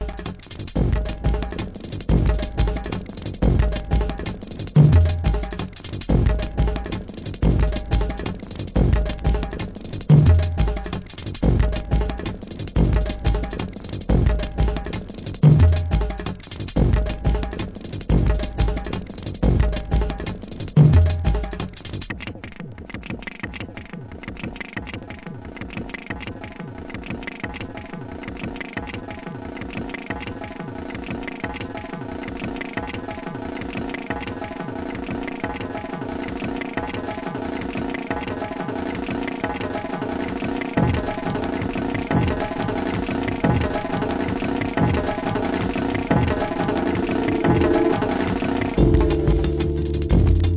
Electronic, ブレイクビーツ